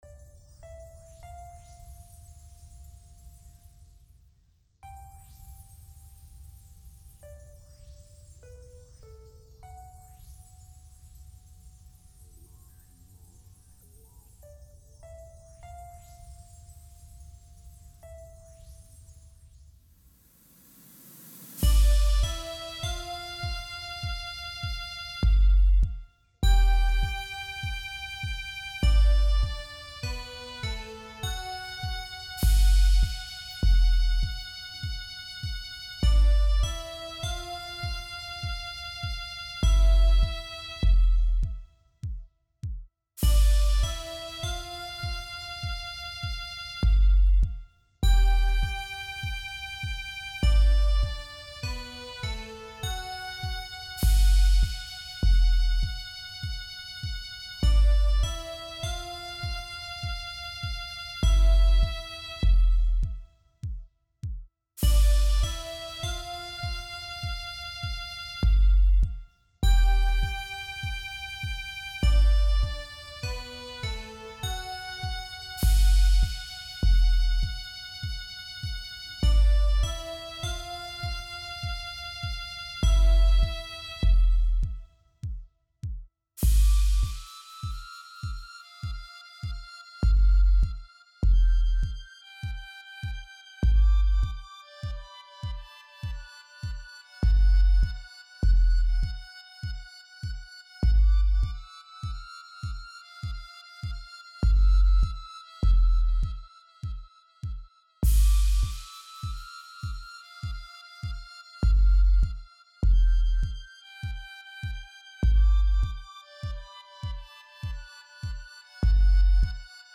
Home > Music > Beats > Medium > Laid Back > Floating